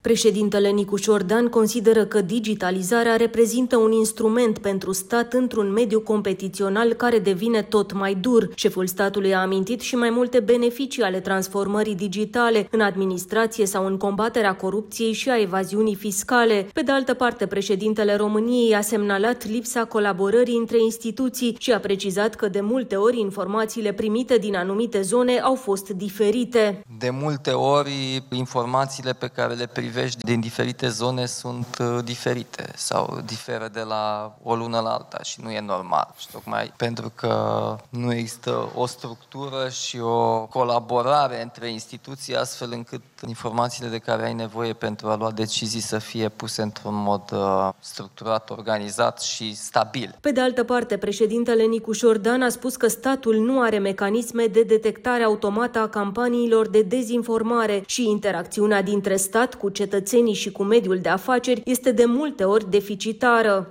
Semnalul de alarmă a fost tras de preşedintele Nicuşor Dan la Summitul pentru Guvernanţă Digitală 2025.